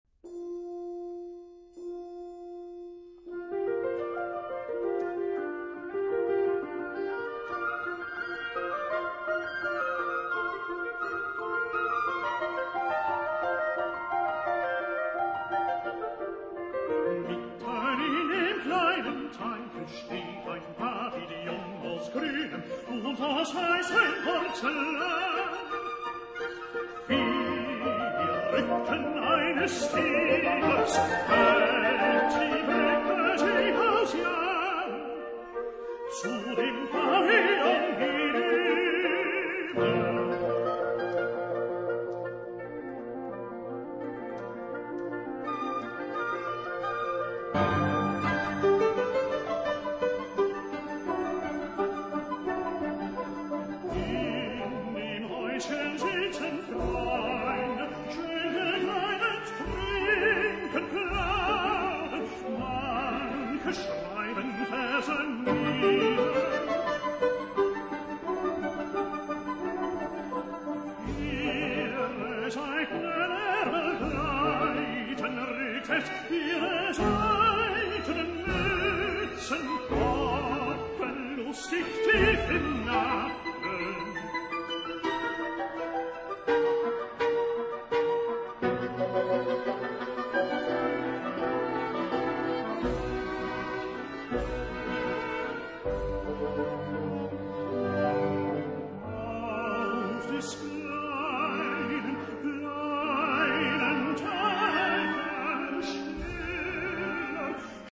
把大樂團的氣勢，轉換為更精緻的氛圍氣息。